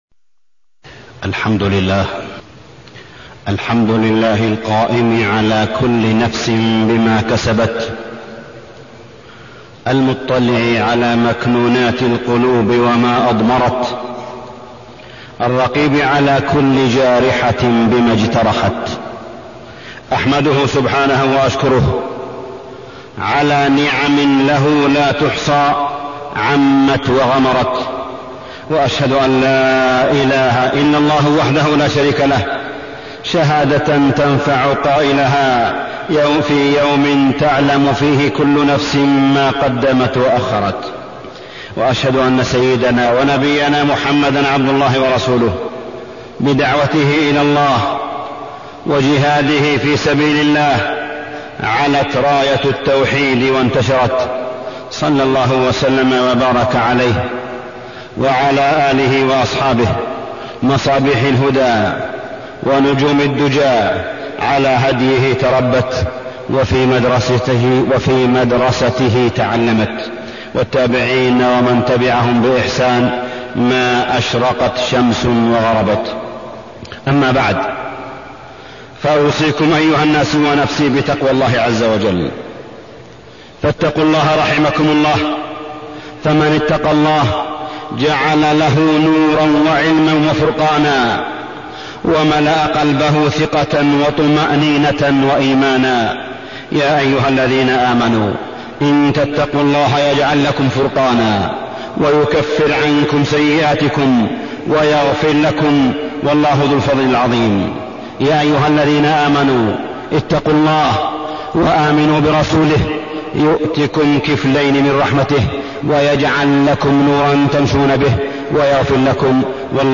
تاريخ النشر ١٣ جمادى الآخرة ١٤٢٢ هـ المكان: المسجد الحرام الشيخ: معالي الشيخ أ.د. صالح بن عبدالله بن حميد معالي الشيخ أ.د. صالح بن عبدالله بن حميد التربية والتعليم